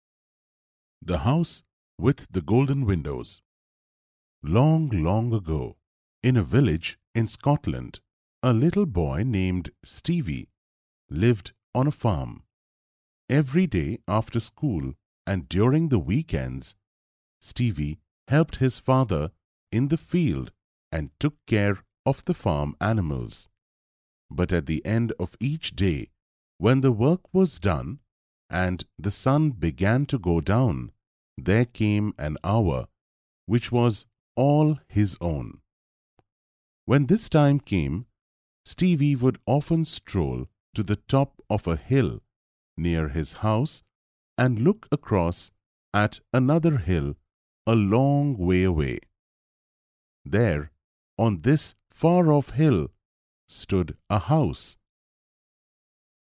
Chapter Narration
Page-1 Narration